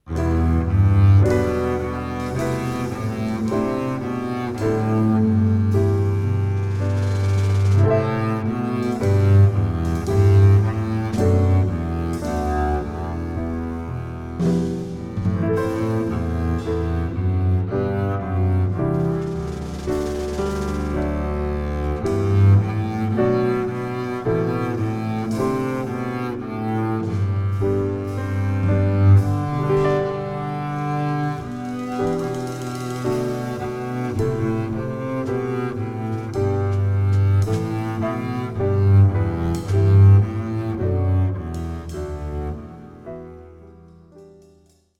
at Studio Happiness